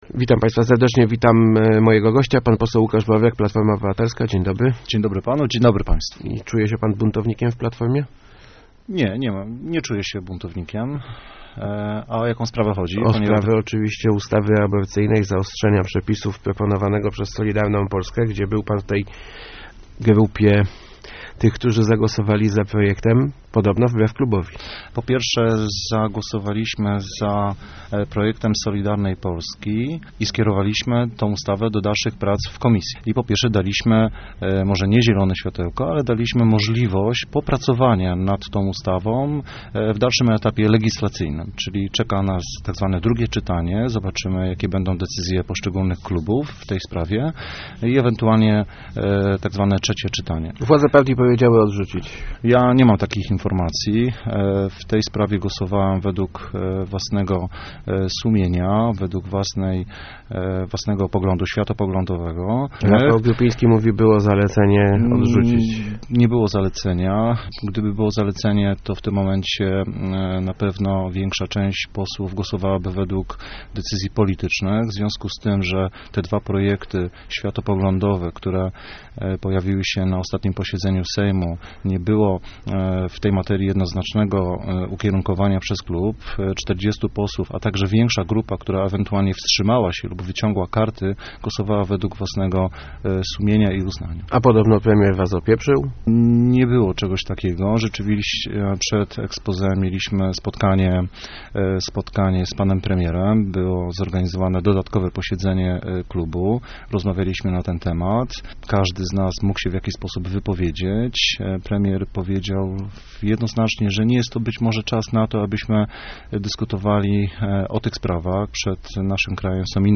lbor0705.jpgMieszkańcy ziemi leszczyńskiej powinni się cieszyć, że z ust premiera padła tak konkretna zapowiedź dotycząca budowy S5 - mówił w Rozmowach Elkiposeł PO Ł kasz Borowiak.